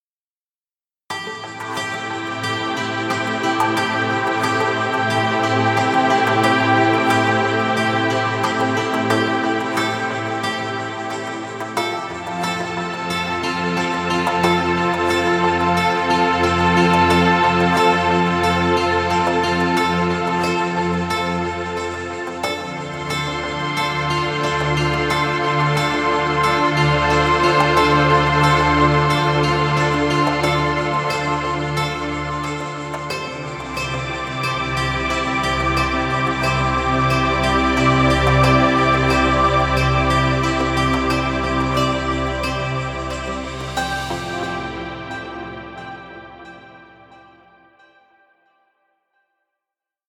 Stock Music.